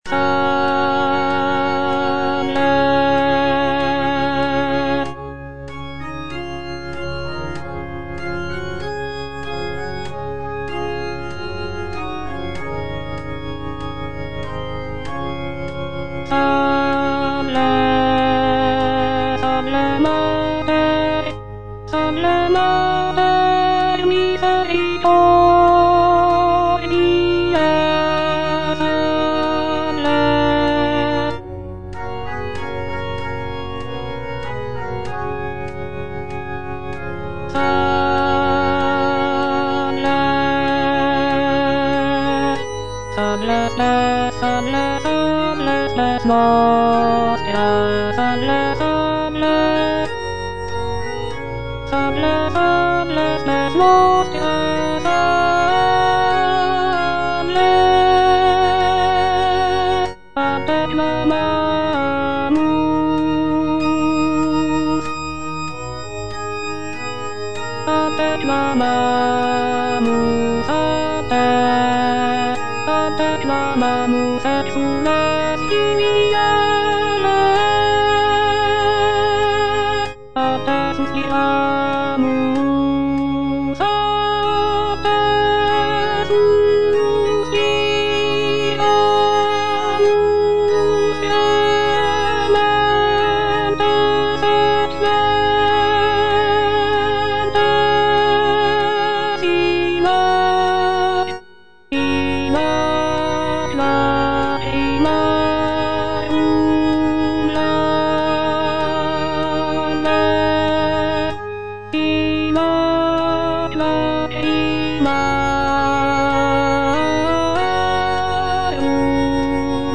Choralplayer playing Salve, Regina (A = 415 Hz) by G.F. Sances based on the edition From
G.F. SANCES - SALVE, REGINA (A = 415 Hz) Alto (Voice with metronome) Ads stop: auto-stop
"Salve, Regina (A = 415 Hz)" is a sacred choral work composed by Giovanni Felice Sances in the 17th century. This piece is a setting of the traditional Latin Marian hymn "Salve Regina" and is performed in a lower pitch of A = 415 Hz, which was common in the Baroque era. The work features rich harmonies, expressive melodies, and intricate vocal lines, showcasing Sances' skill as a composer of sacred music.